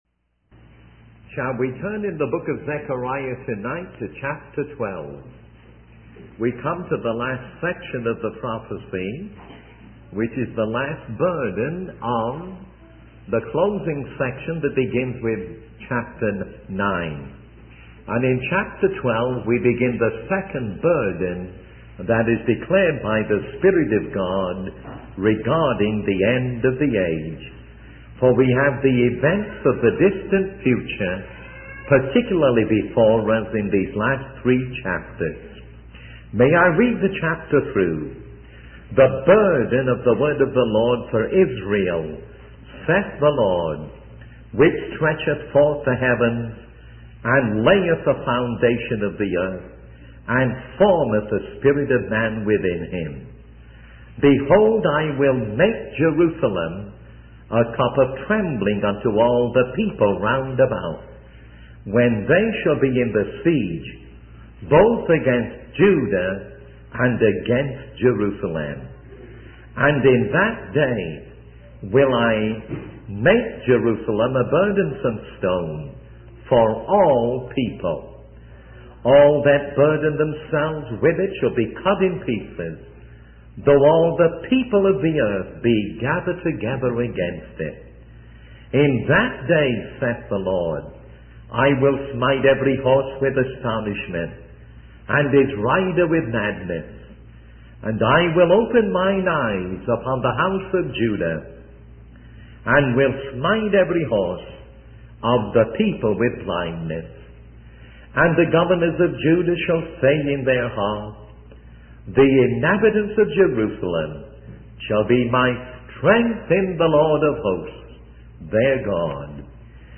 In this sermon, the speaker focuses on the last section of the book of Zechariah, specifically chapters 12, 13, and 14. These chapters discuss events related to the closing of the Gentile Age, the final great tribulation, and the personal return of Jesus to the earth.